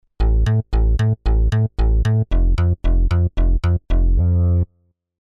Подскажите, где найти простенький бас